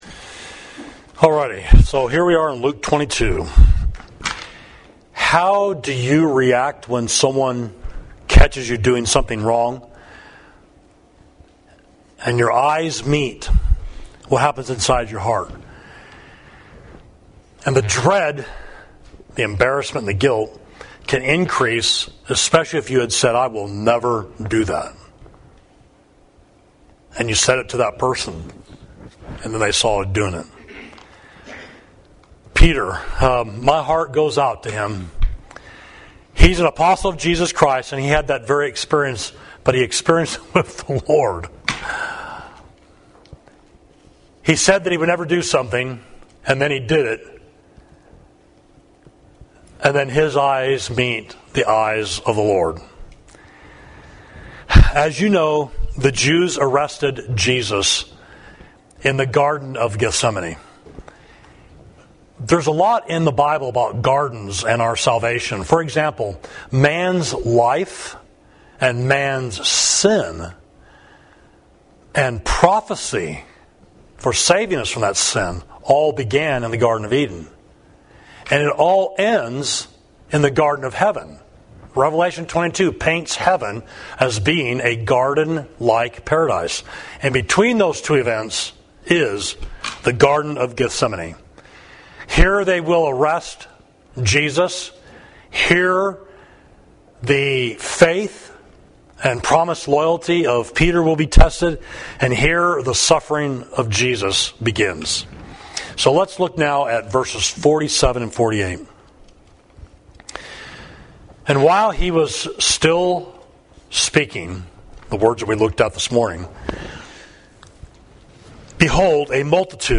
Sermon: When the Lord Looked at Peter, Luke 22.47–62